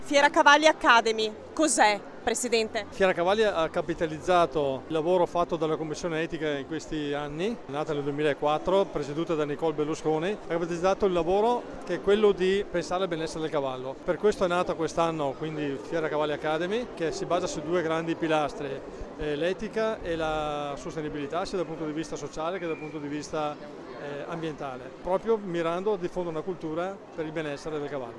Presenti all’inaugurazione, intervistati dalla nostra corrispondente